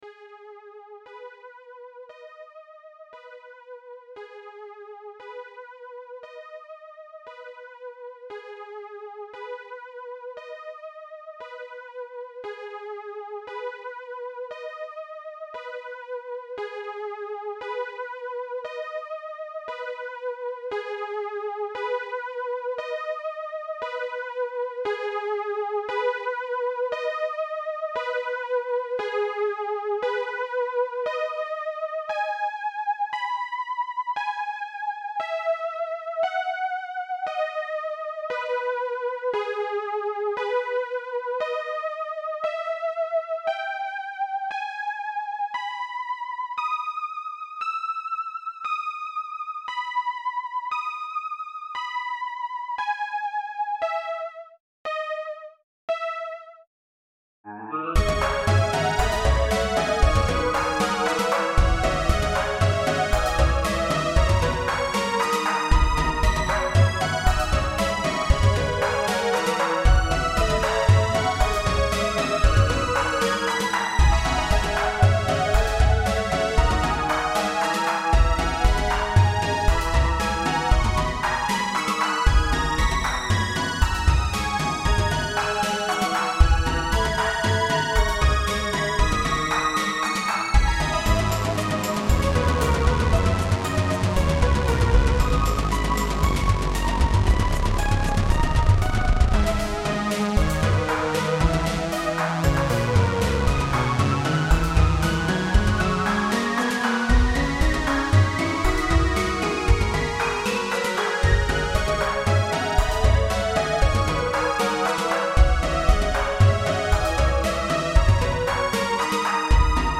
Bit more adventurous and less ominous than the existing Outer End music, feels very dramatic.